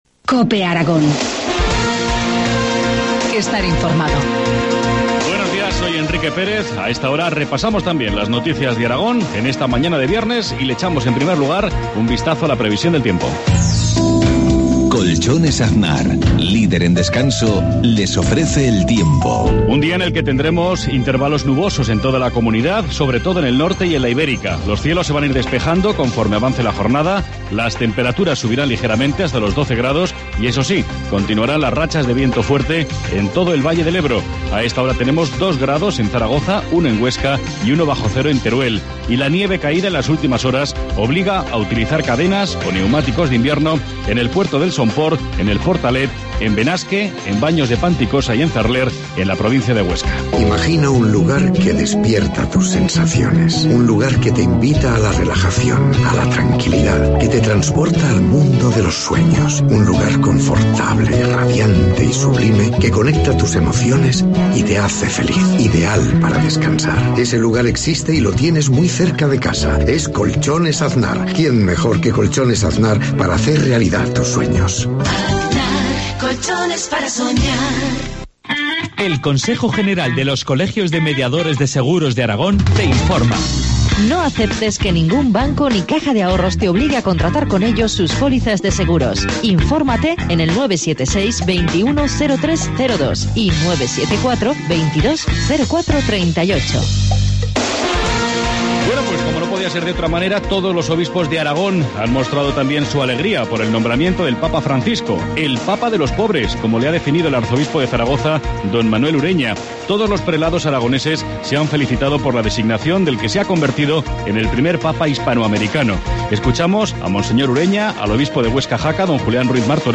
Informativo matinal, viernes 15 de marzo, 7.53 horas